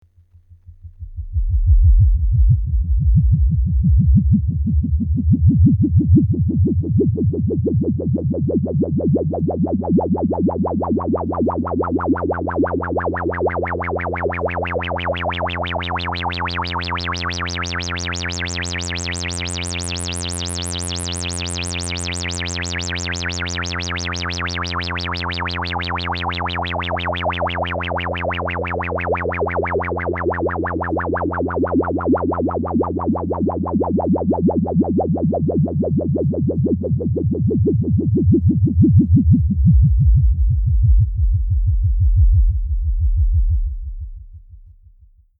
Example sounds, no effects used, input signal is static saw wave from function generator:
filter sweep wobble, high Q
ldr-filtersweep-wobble-saw88hz-highq.mp3